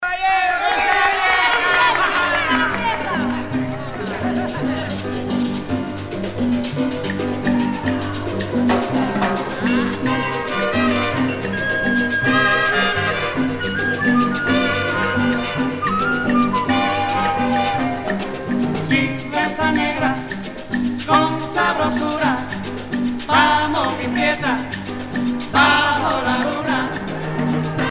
danse : rumba